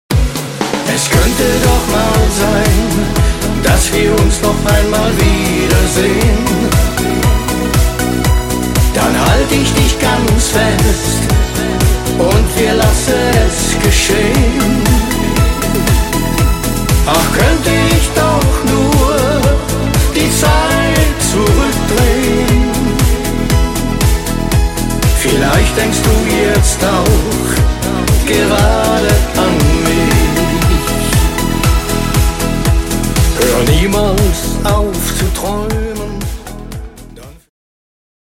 Gitarren, Keyboard Programming